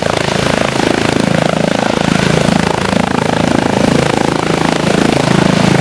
blade_vortex.wav